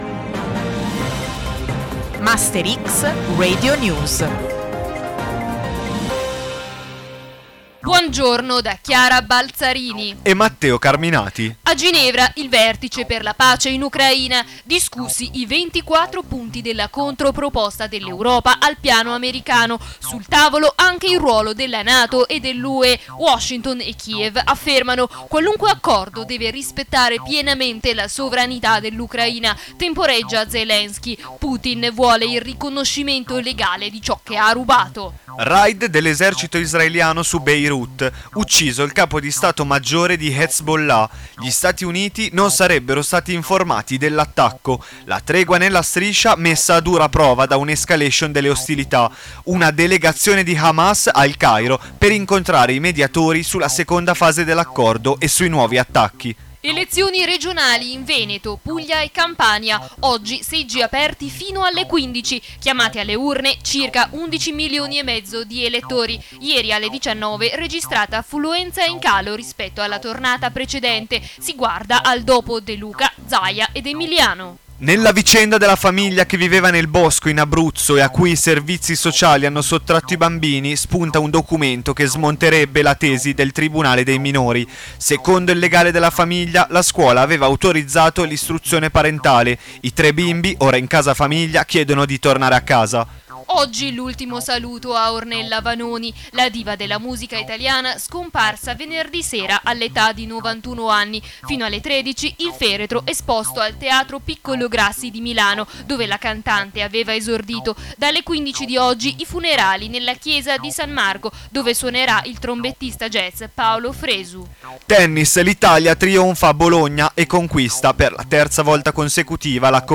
GR 24 NOVEMBRE